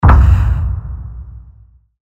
機械の動作音、爆発音などがダウンロードできます。
大砲を発射する効果音